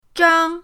zhang1.mp3